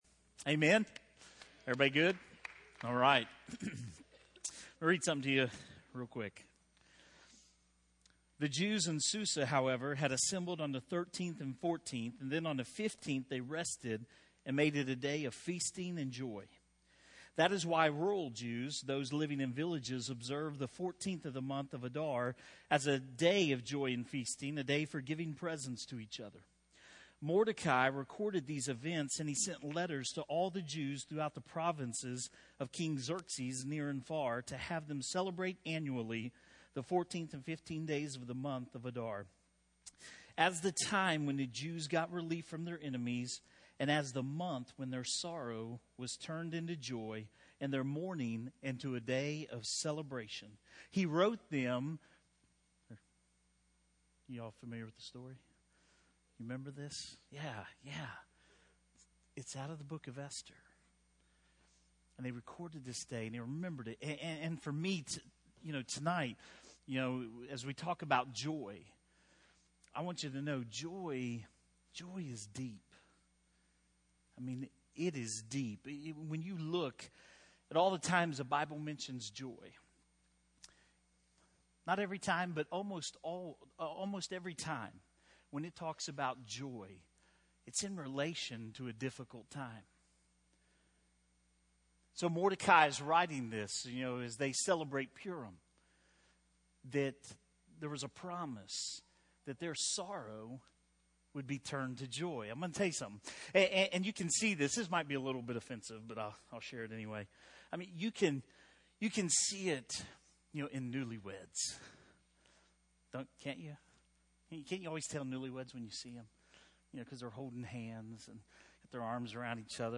Online Audio Sermons